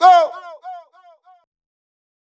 Vox (Domo 23).wav